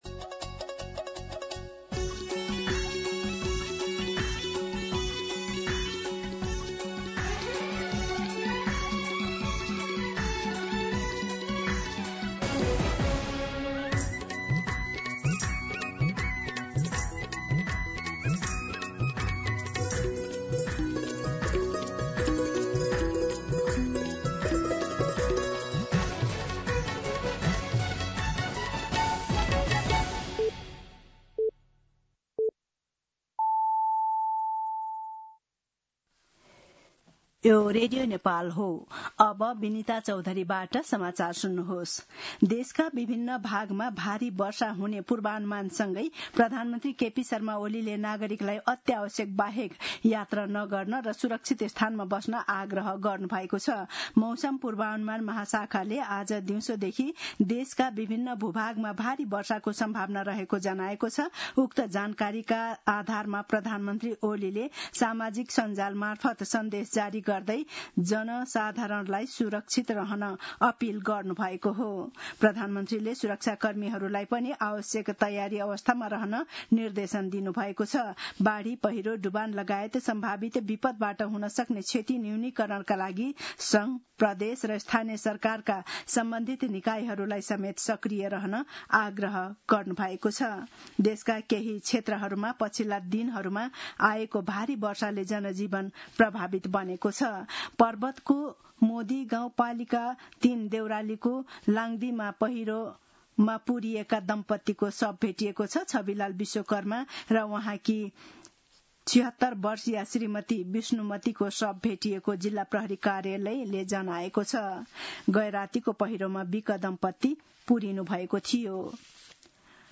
दिउँसो ४ बजेको नेपाली समाचार : ४ साउन , २०८२